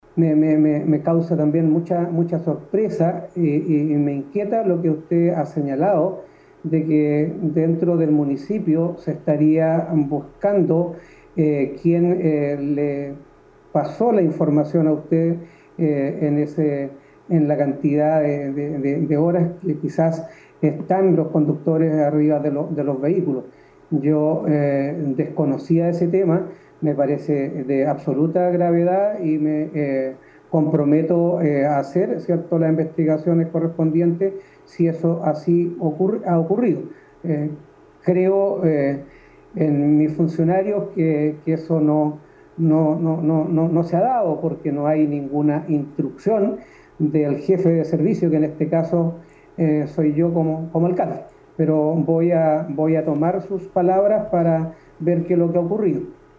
13-ALCALDE-RESPUESTA-2.mp3